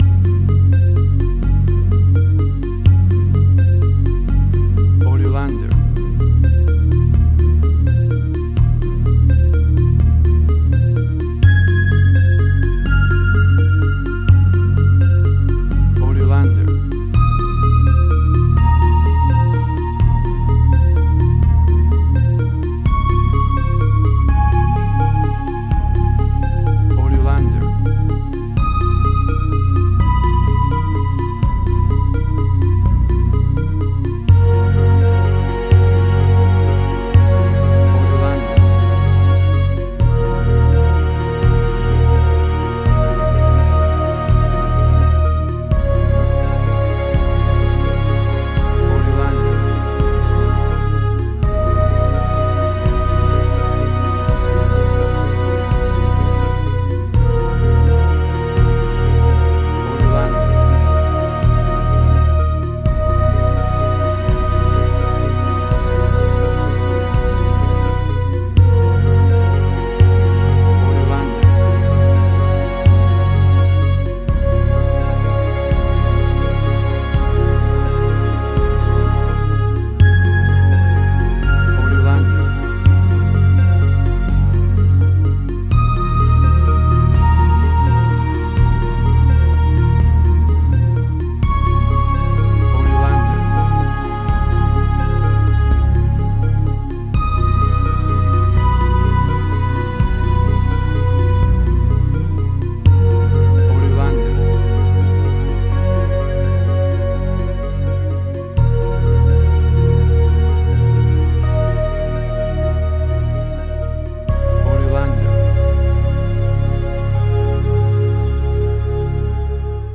Future Retro Wave Similar Stranger Things New Wave.
Tempo (BPM): 84